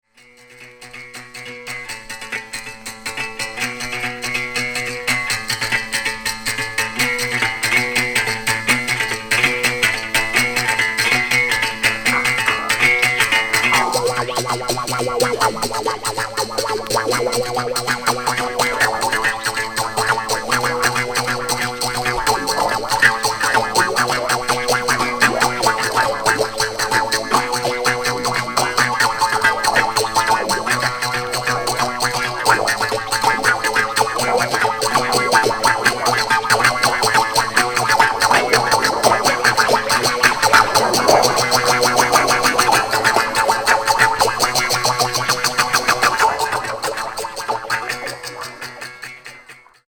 即興　アフロ　電子音